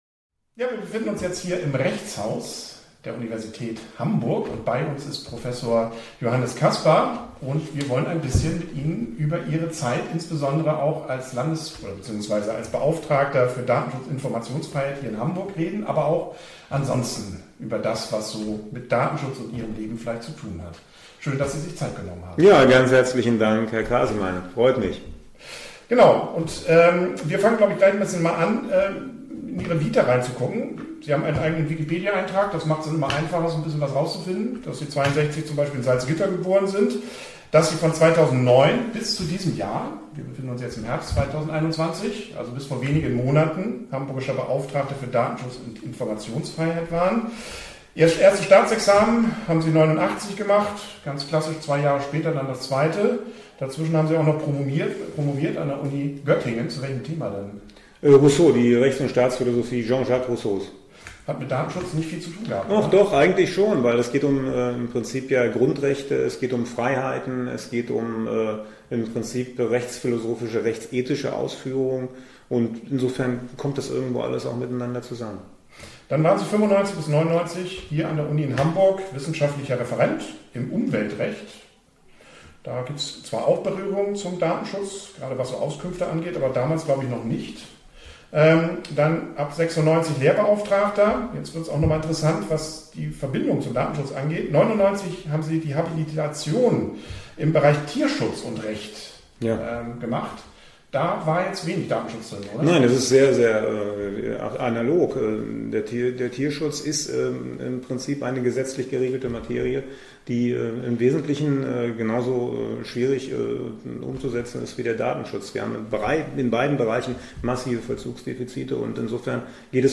Videointerviews
interview_caspar.mp3